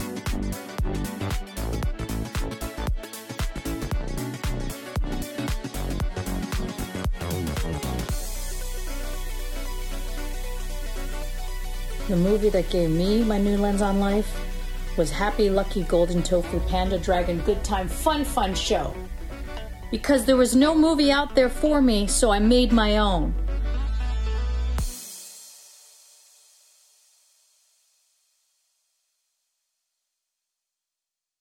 (captured from the vimeo livestream)